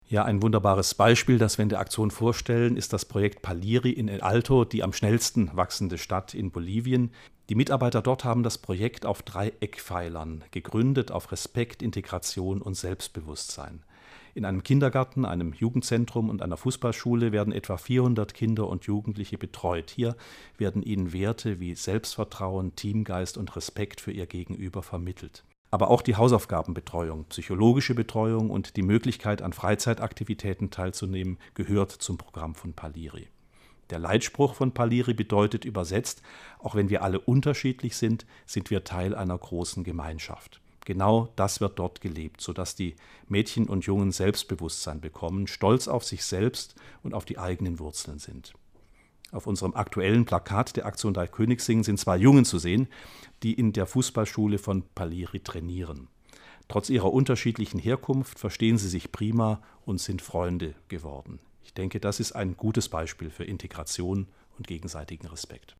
Die Sternsinger' im Interview